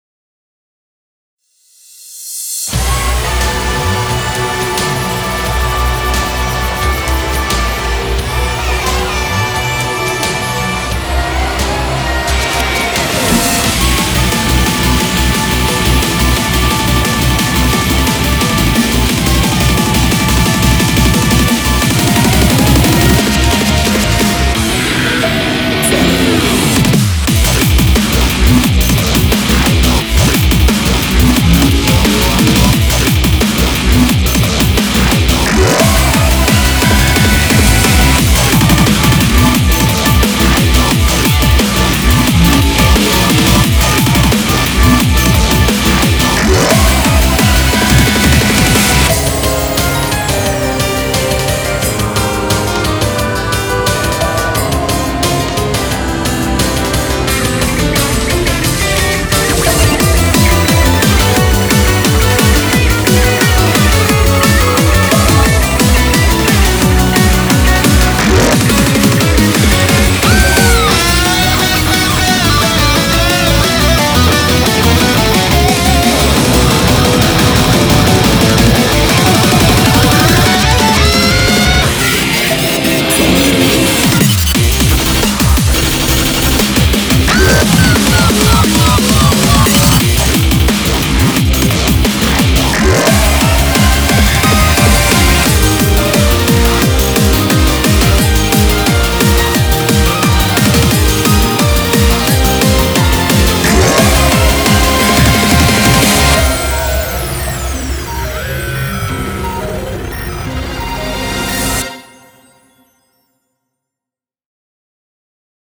BPM88-352
Audio QualityPerfect (High Quality)
TIP: Main BPM is 176.